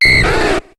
Cri de Minidraco dans Pokémon HOME.